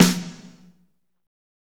Index of /90_sSampleCDs/Northstar - Drumscapes Roland/DRM_AC Lite Jazz/SNR_A_C Snares x